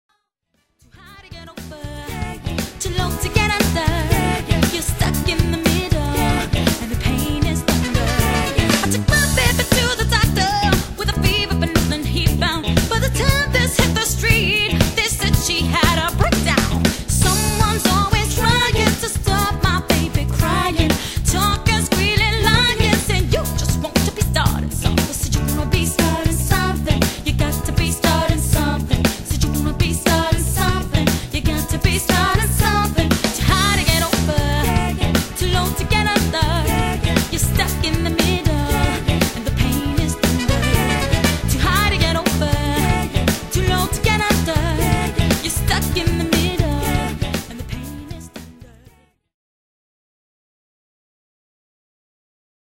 cover bands
funk and disco 70's
brass & multiple vocalists